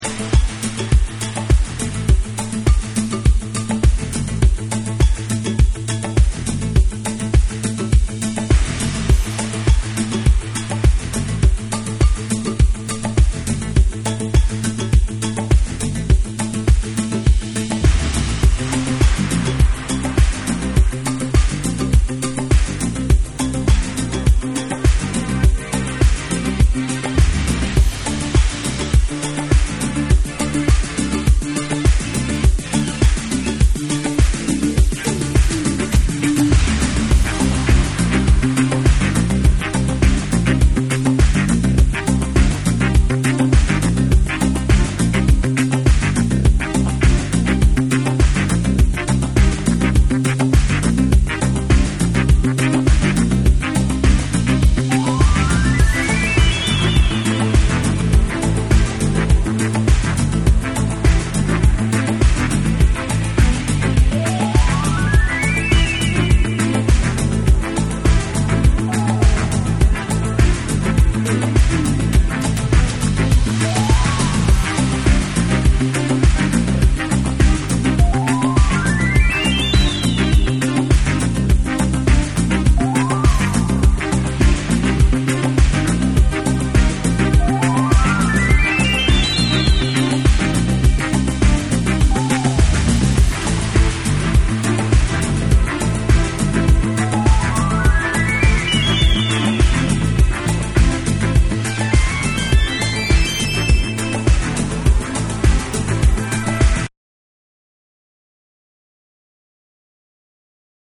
スペイシーなシンセやトリッピーな効果音が炸裂したコズミック・ディスコ
TECHNO & HOUSE